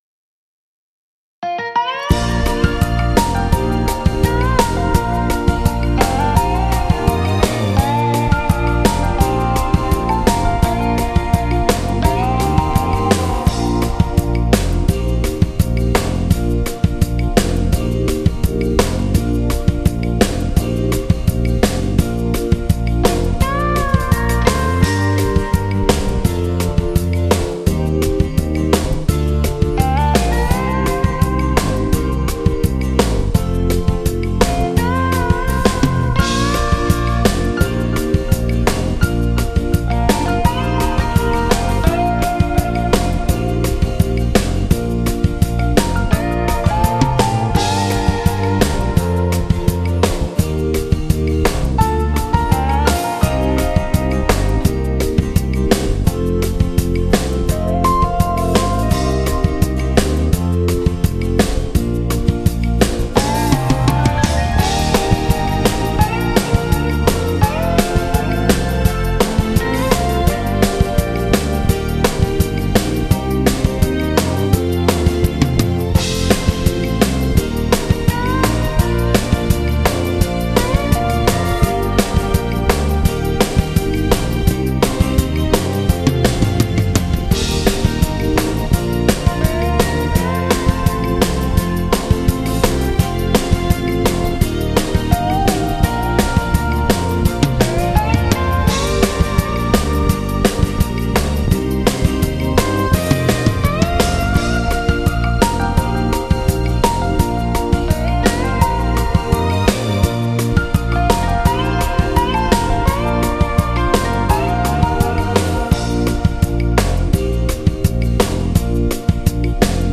Genere: Moderato
Scarica la Base Mp3 (3,51 MB)